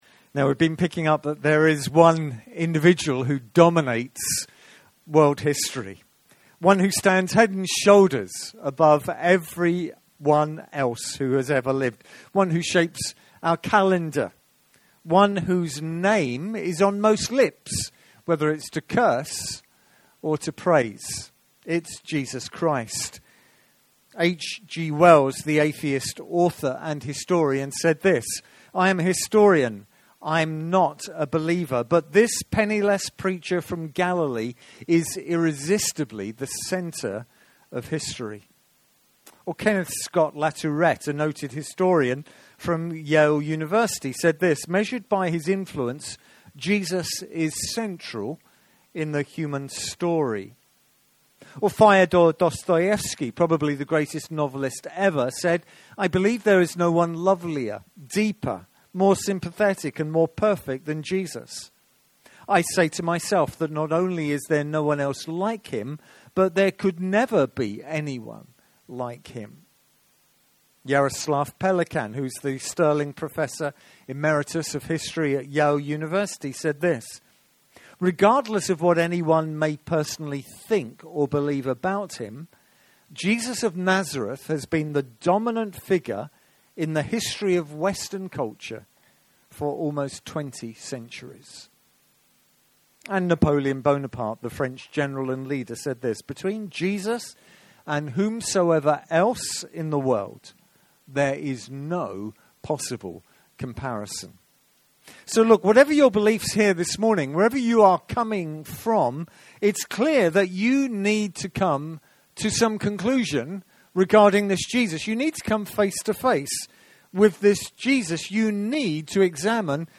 Sermons Archive - Ambassador International Church podcast